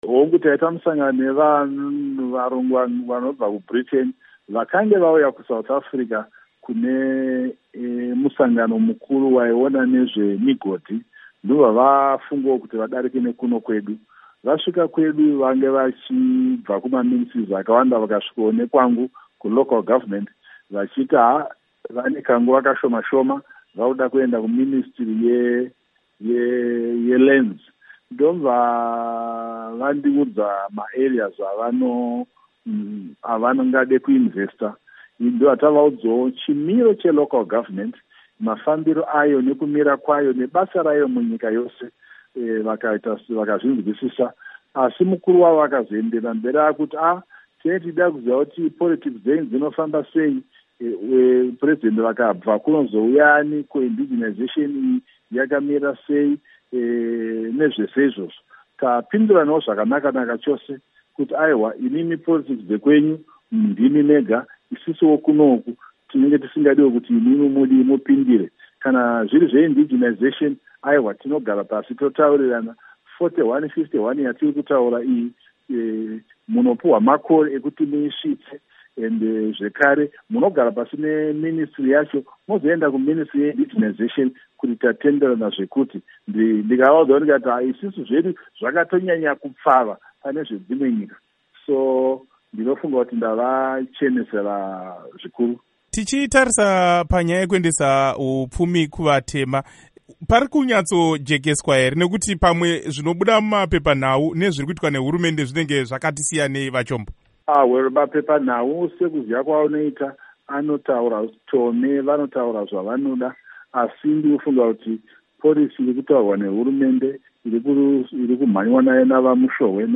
Hurukuro naVaIgnatius Chombo